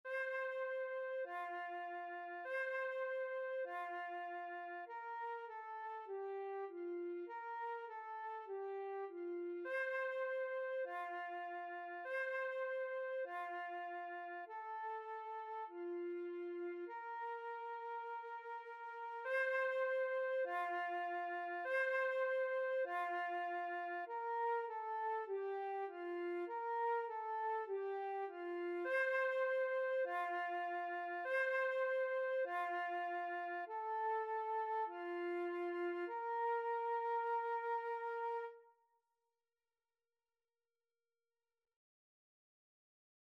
4/4 (View more 4/4 Music)
F5-C6
Instrument:
Classical (View more Classical Flute Music)